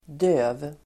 Uttal: [dö:v]